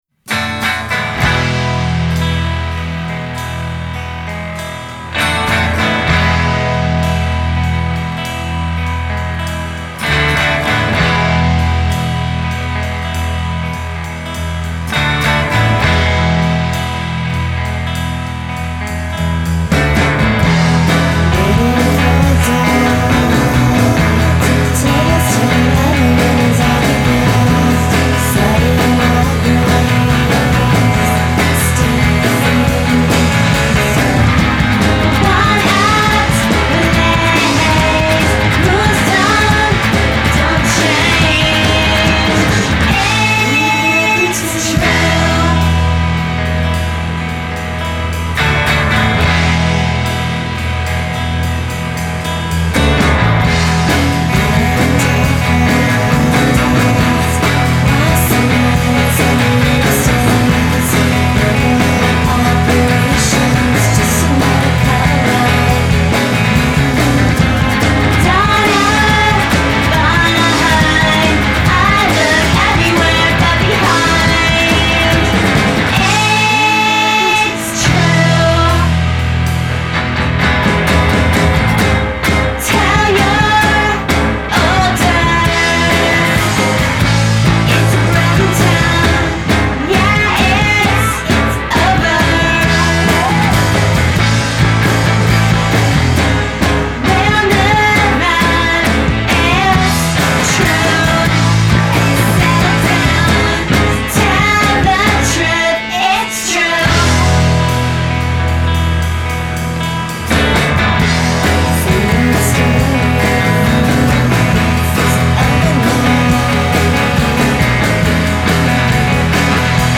fuzz-laden garage rock, and as a grungy female vocalist